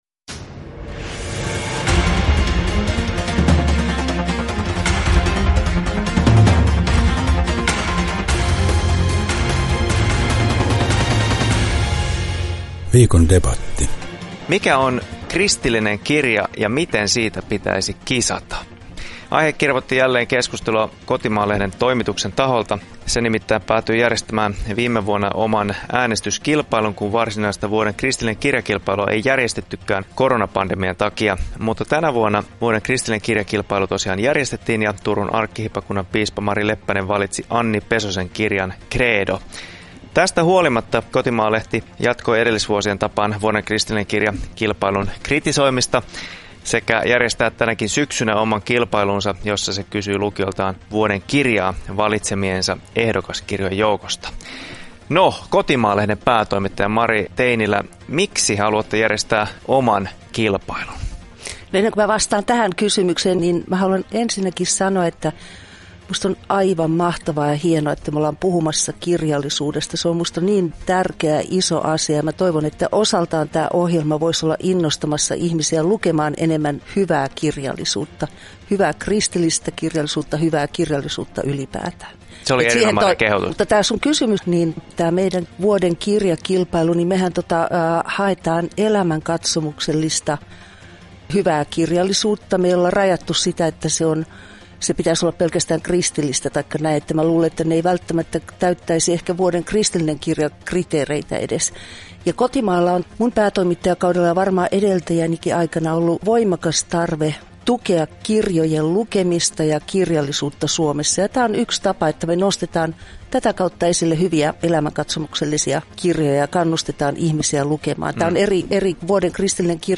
KUUNTELE: Viikon debatti - Vuoden kristillinen kirja vai Vuoden kirja?